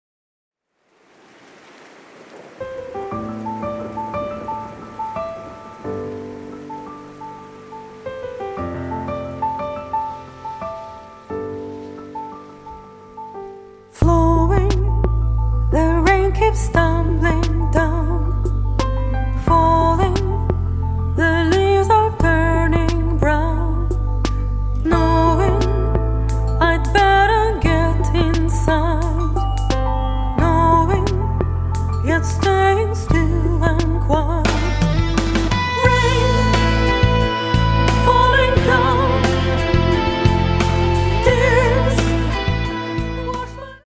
Bass
Drums
Guitar
Keyboards
Backup vocals
Mastered at Abbey Road Studios, London